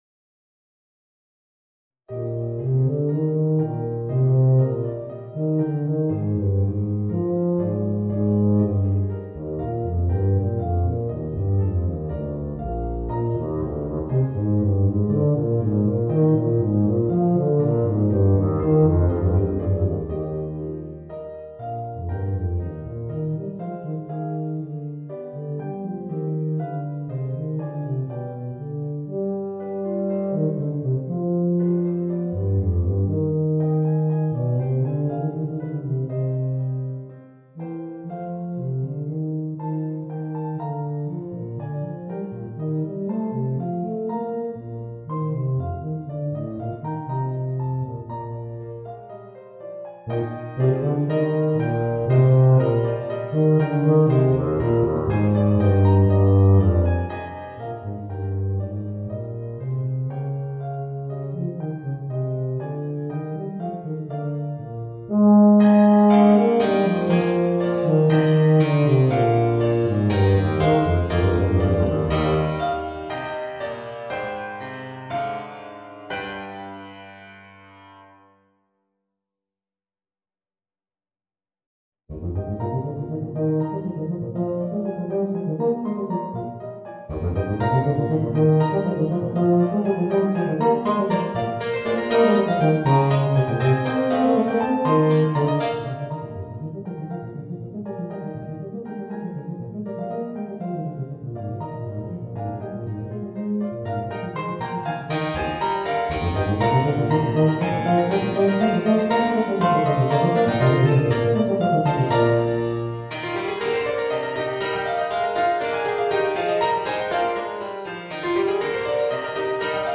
Voicing: Eb Bass and Organ